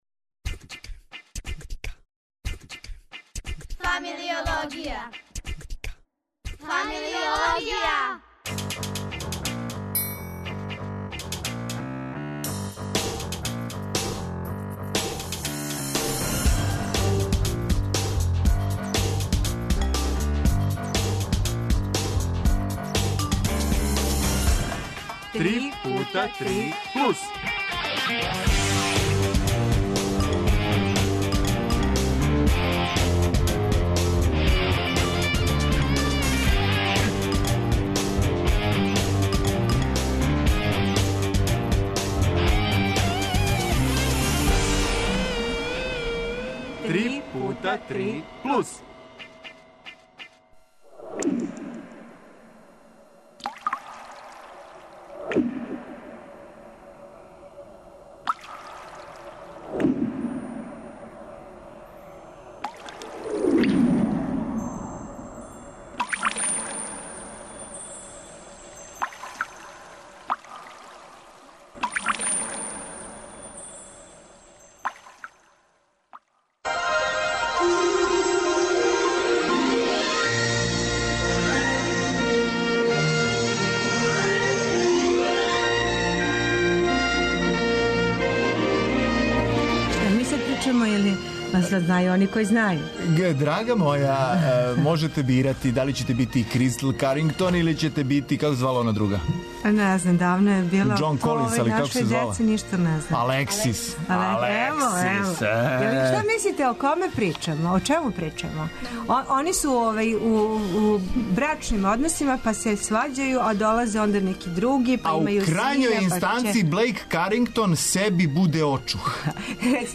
Гости су нам деца и одрасли из Првог београдског певачког друштва... без зеца...